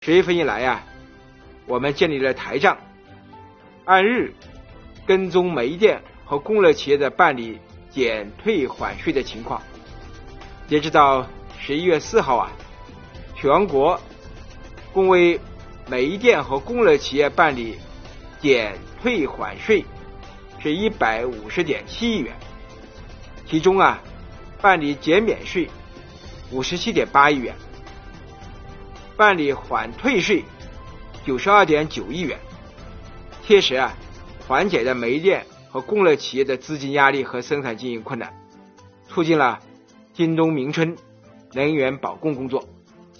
11月5日，国务院新闻办公室举行国务院政策例行吹风会，国家税务总局相关负责人介绍制造业中小微企业缓税政策等有关情况，并答记者问。